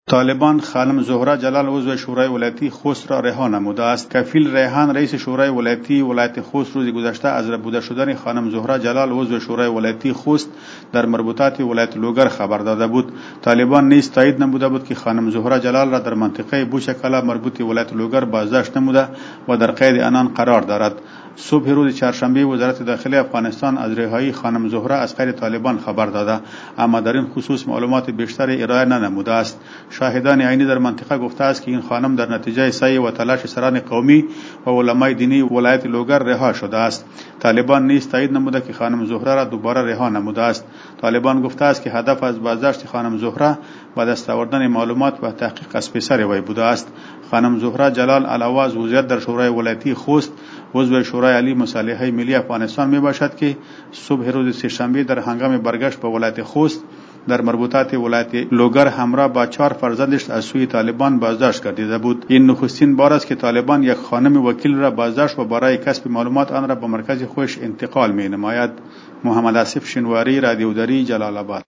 به گزارش خبرنگار رادیودری، منابع می‌گویند که زهرا جلال، عضو شورای ولایتی خوست و عضو شورای عالی مصالحۀ ملی که روز گذشته با ۴ پسرش در لوگر از سوی طالبان ربوده شده بود، با میانجیگری بزرگان قوم رها شد.